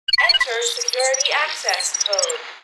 PasscodeRequired.wav